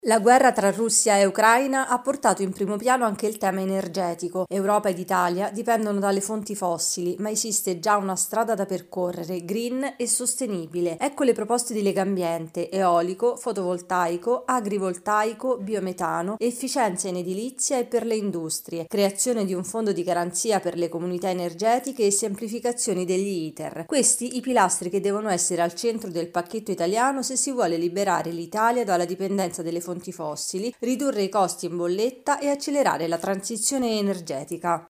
Il messaggio di Legambiente al Presidente del Consiglio e alla Commissione Europea. Il servizio